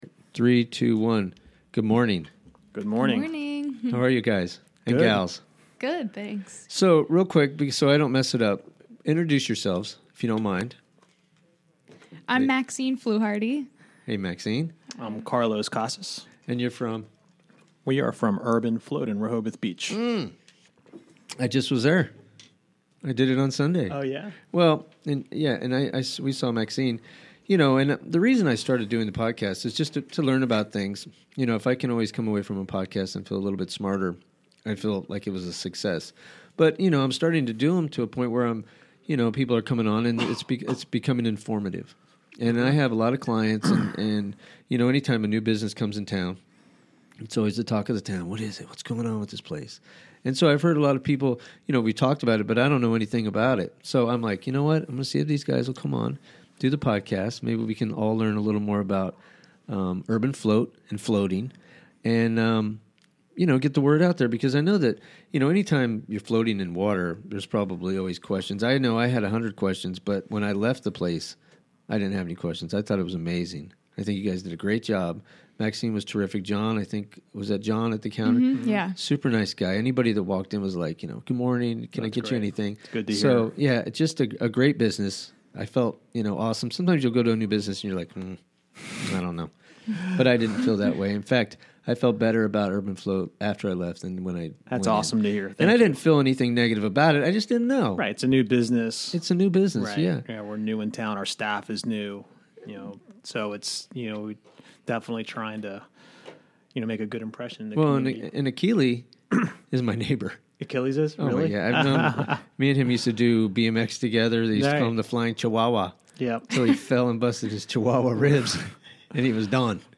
Chatting with local folks